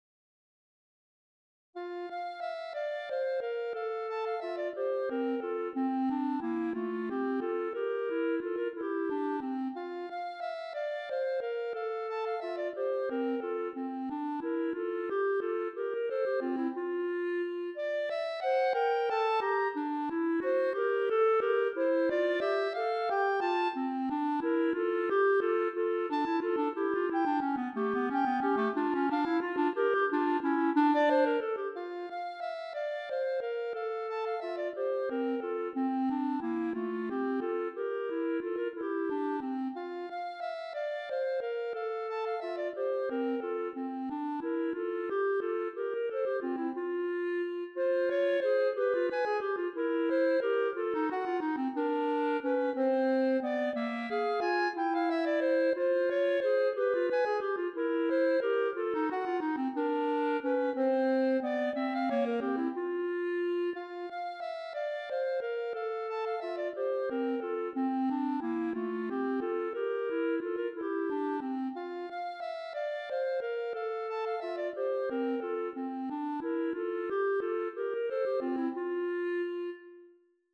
Voicing: Clarinet Duet